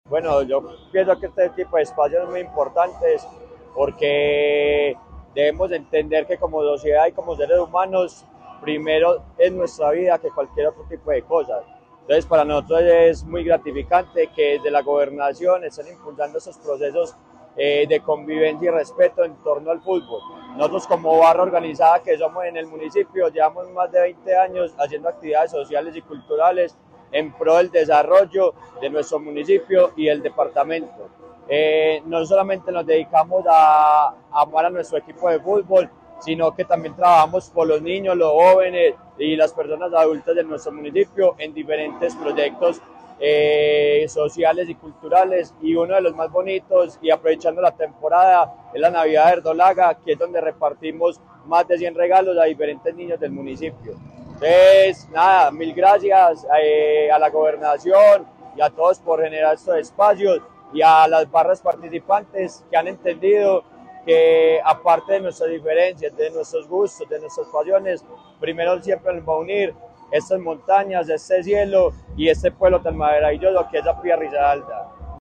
Barrista Atlético Nacional.